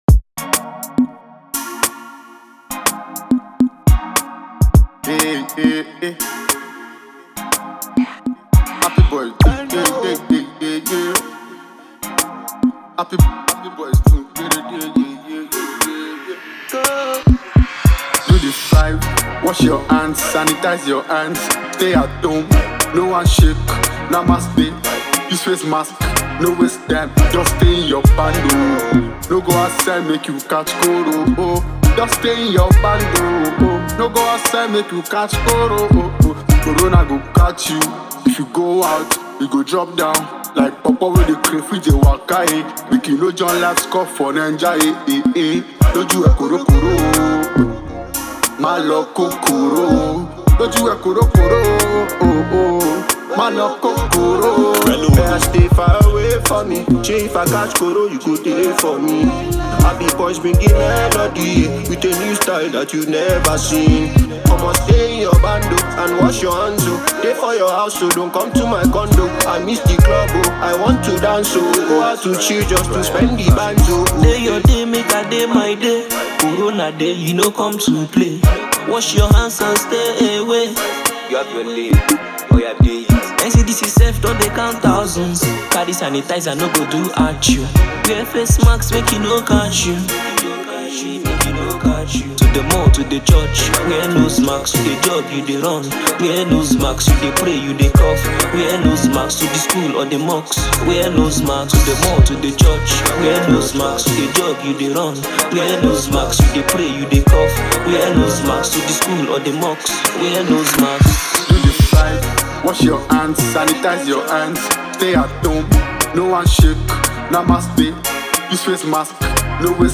afro-trap vibe